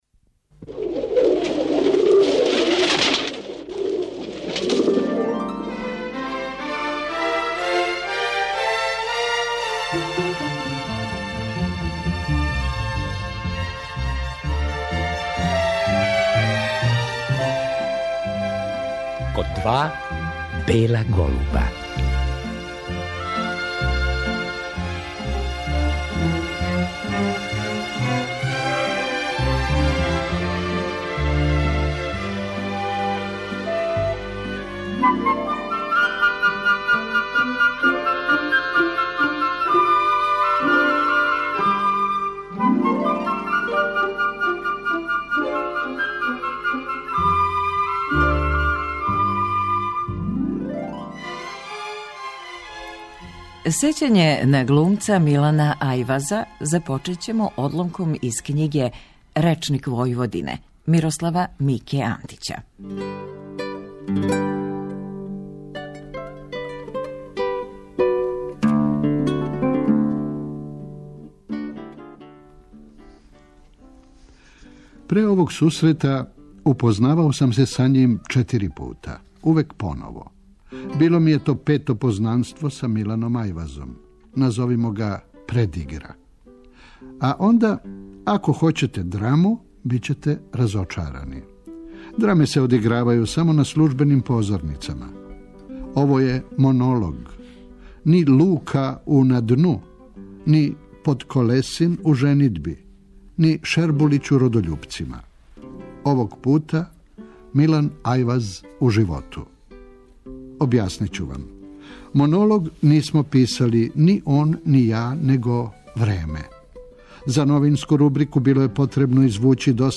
Тај разговор чућемо у вечерашњој емисији.